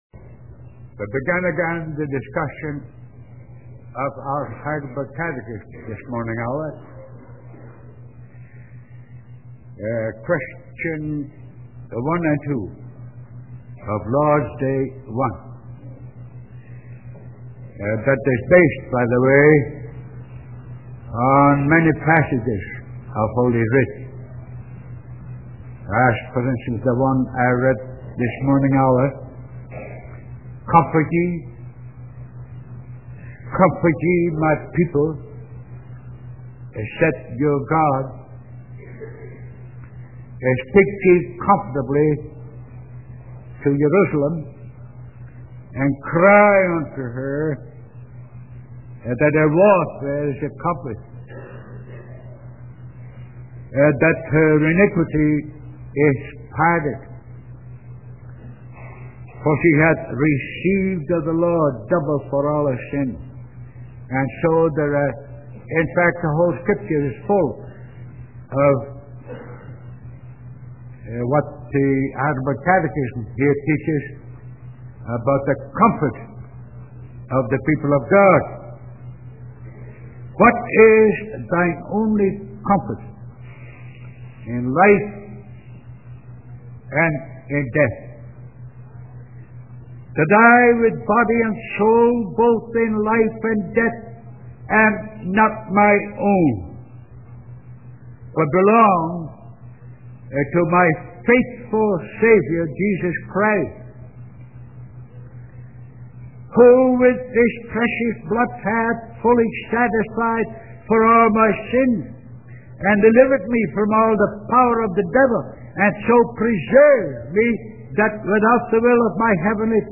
This is an audio sermon